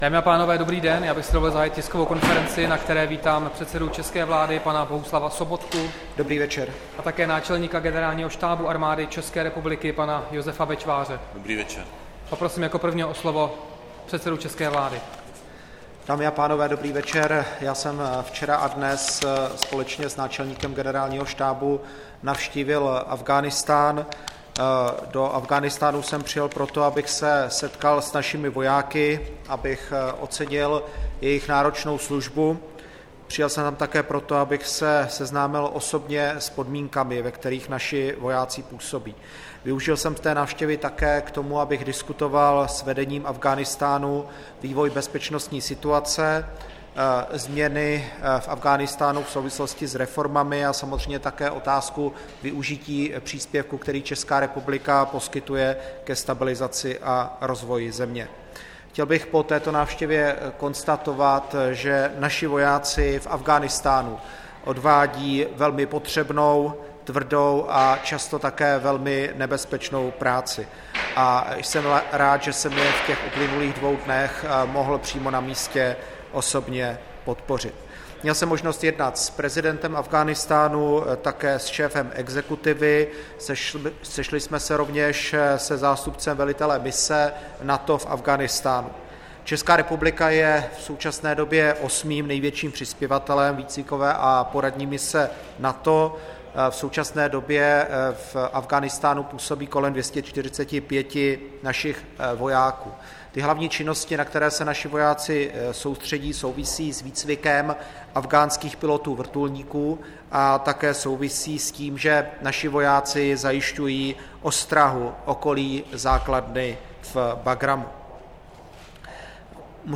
Tisková konference po návštěvě předsedy vlády Bohuslava Sobotky v Afghánistánu, 25. dubna 2016